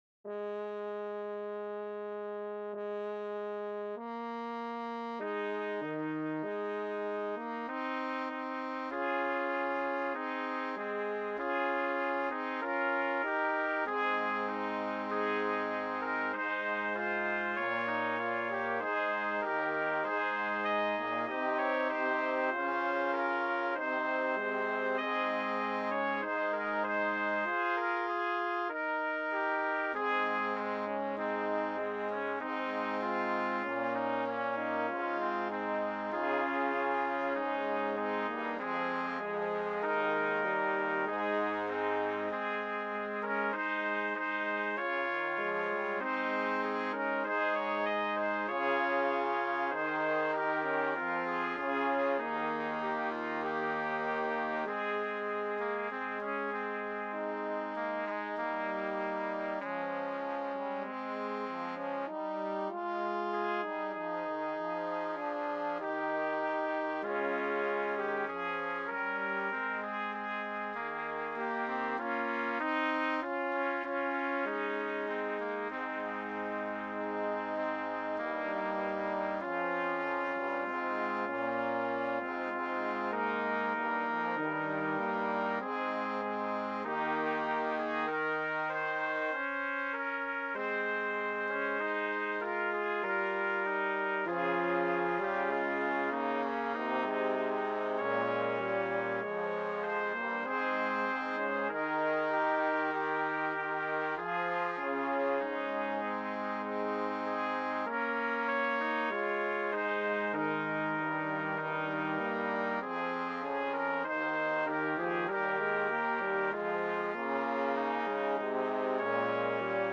BRASS QUINTET
MOTET